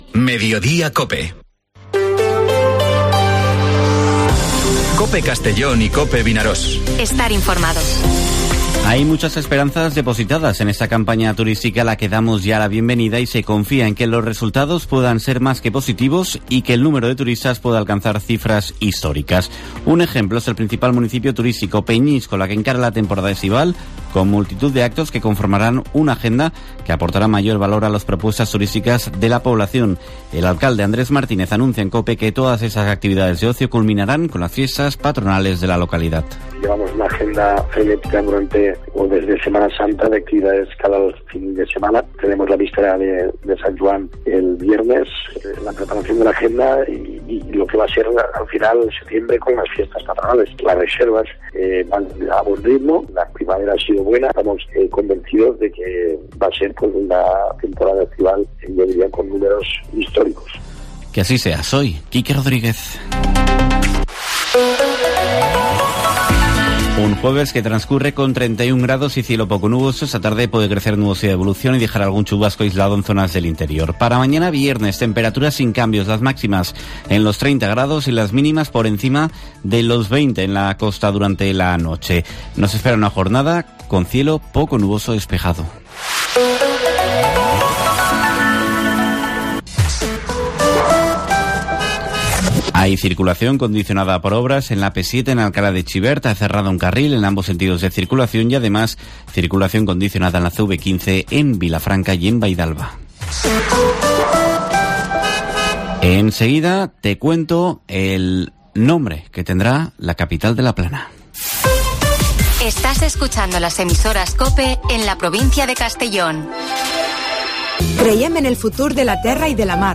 Informativo Mediodía COPE en la provincia de Castellón (22/06/2023)